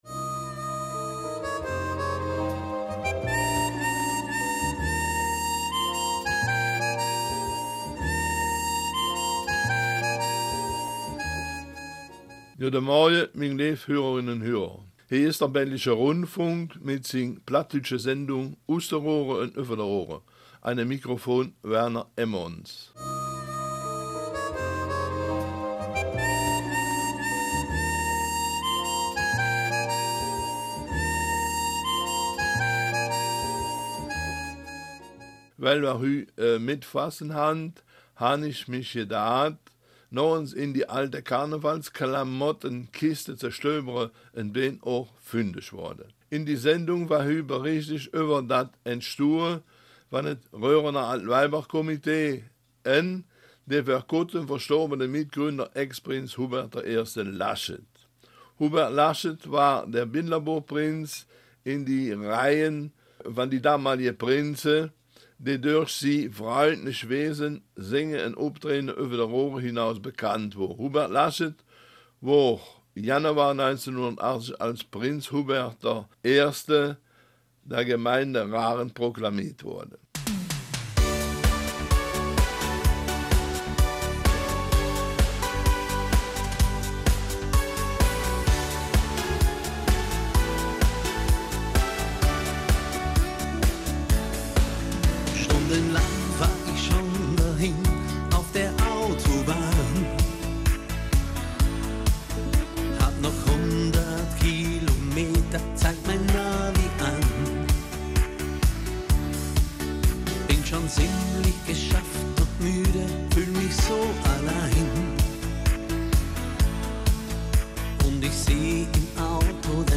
Raerener Mundartsendung auf den Spuren des Altweiberkomitees
Raerener Mundart